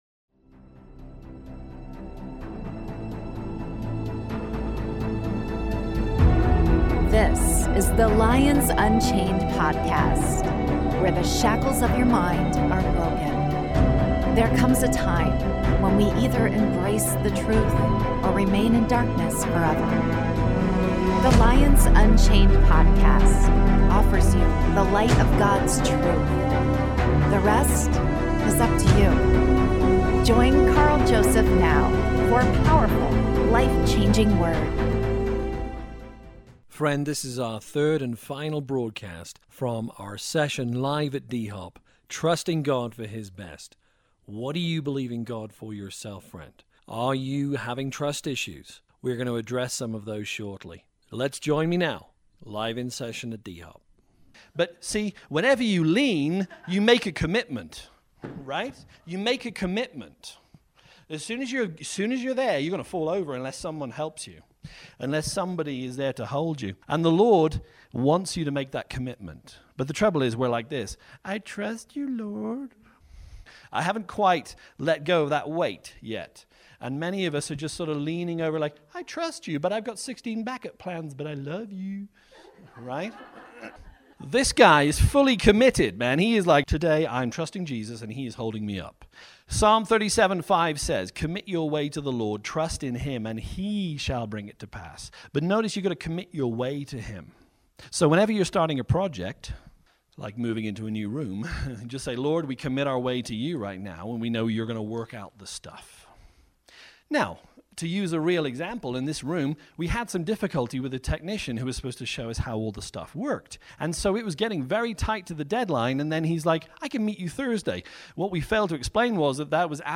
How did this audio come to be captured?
Part 3 (LIVE)